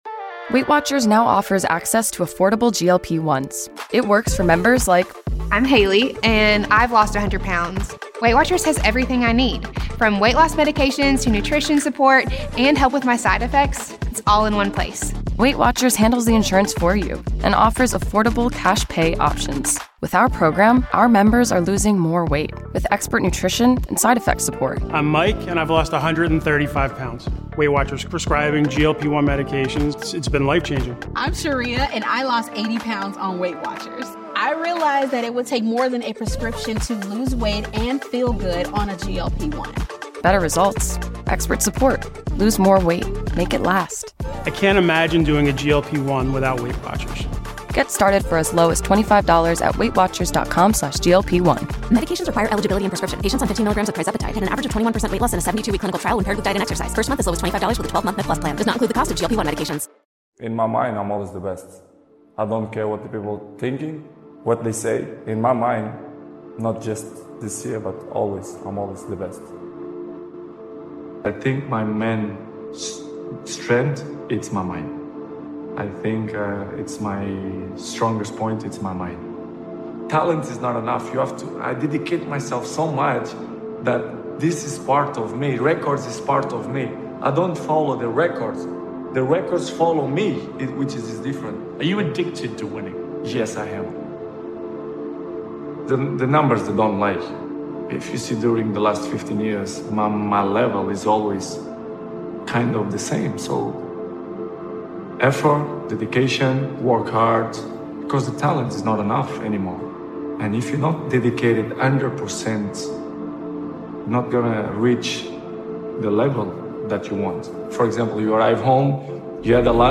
Cristiano Ronaldo - Your main strength is your mind motivational speech